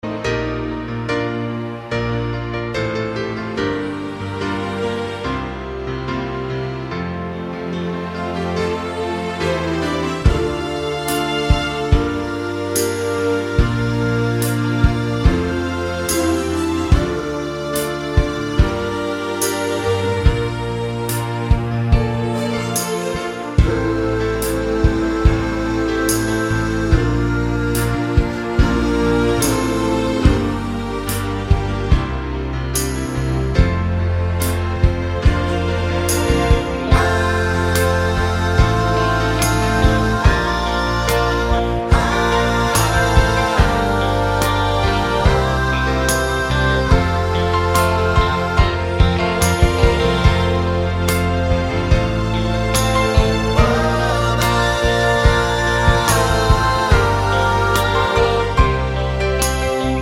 Short Version Rock 3:54 Buy £1.50